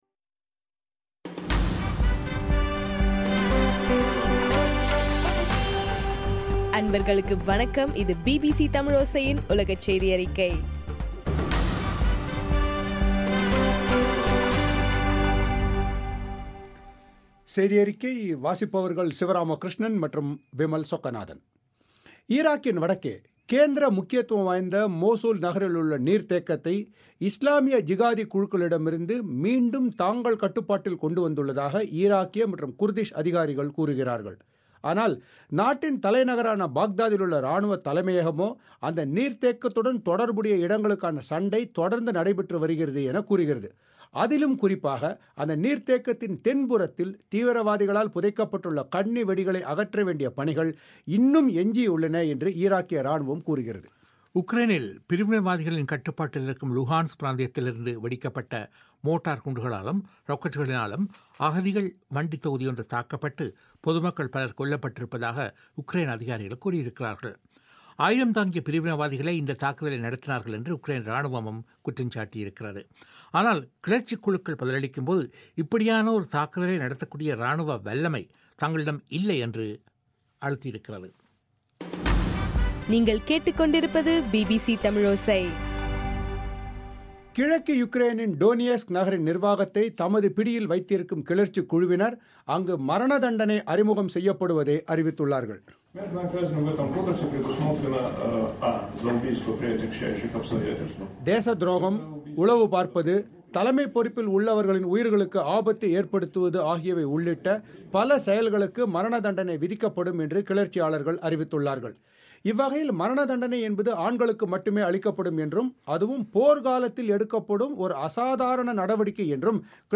இன்றைய ( ஆகஸ்ட் 18) பிபிசி தமிழோசை உலகச் செய்தியறிக்கை